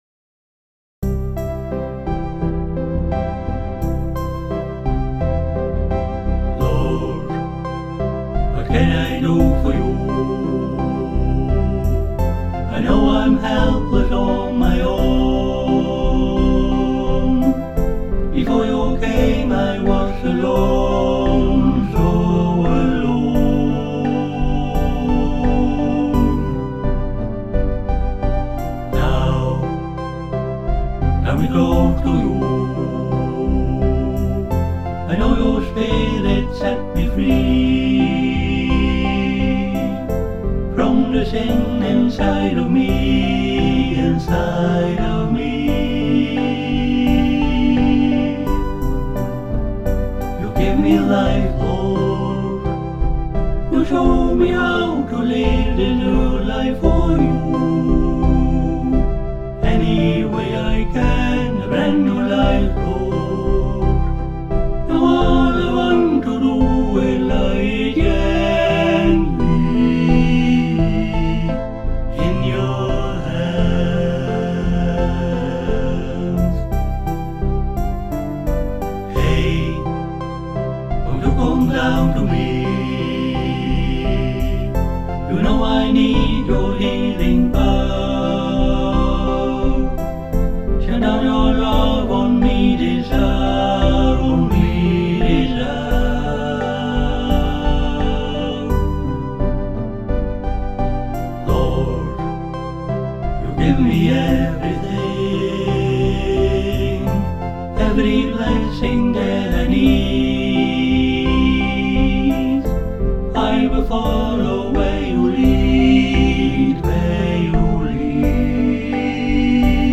Moderately, with devotion